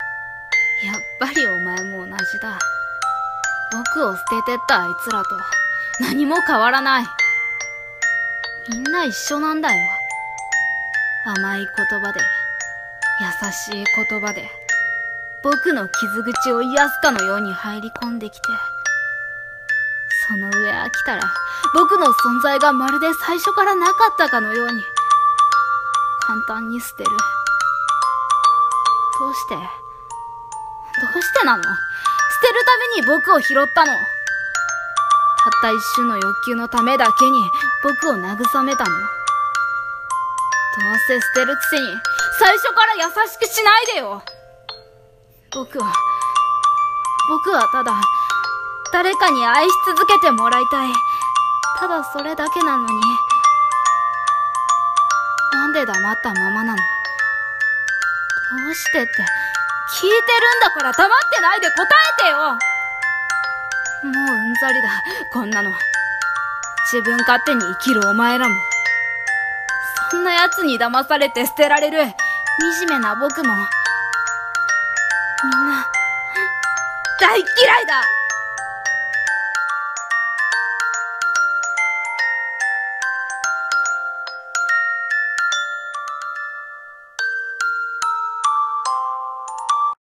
【一人声劇】